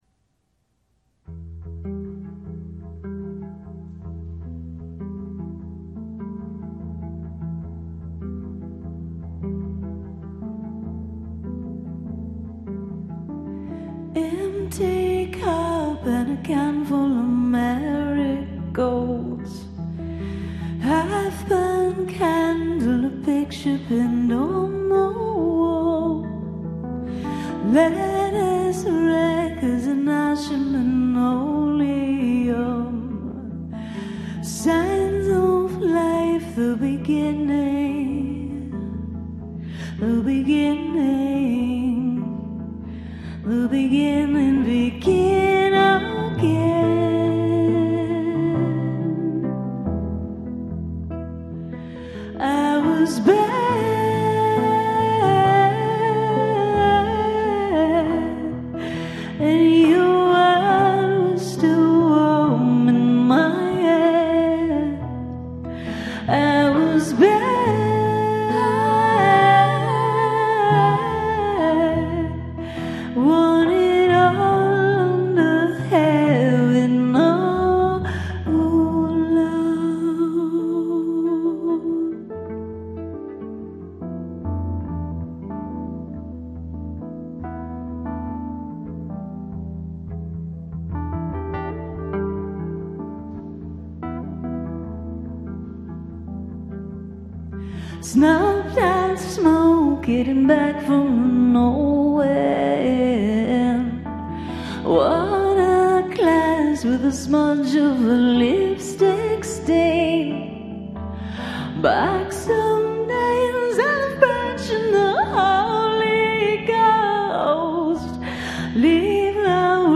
recorded and broadcast from the Prado Museum